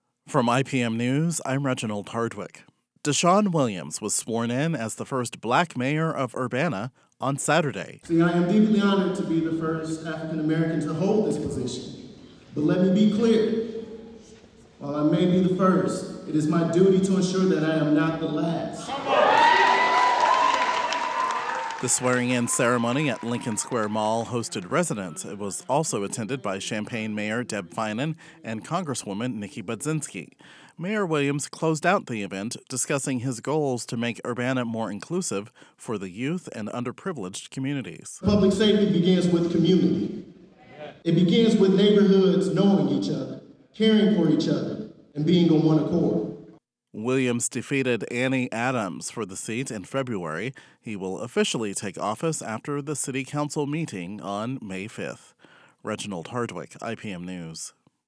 “I’m deeply honored to be the first African American to hold this position, but let me be clear, while I may be the first, it is my duty to ensure that I’m not the last,” said Williams to loud cheers.
The ceremony at Lincoln Square Mall included residents in the audience.